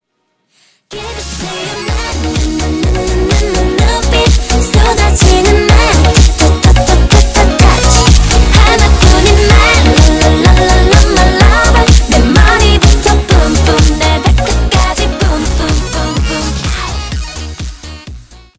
громкие
веселые
мощные басы
озорные
K-Pop
Веселая корейская музыка